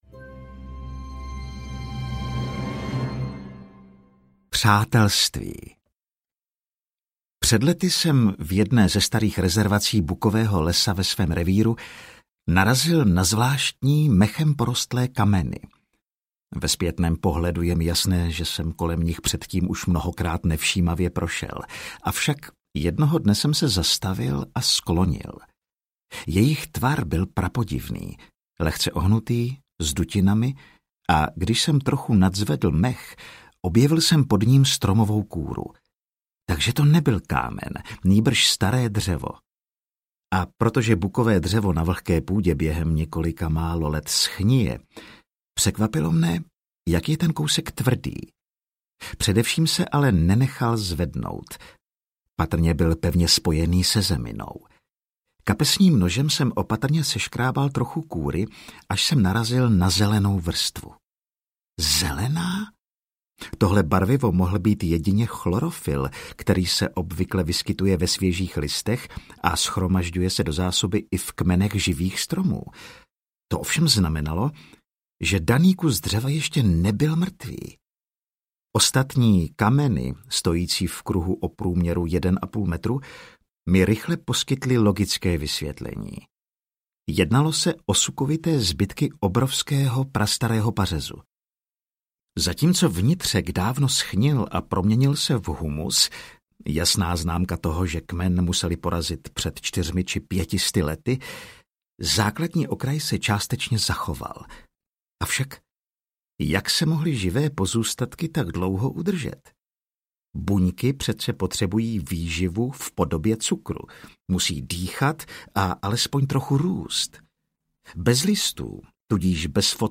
Tajný život stromů audiokniha
Ukázka z knihy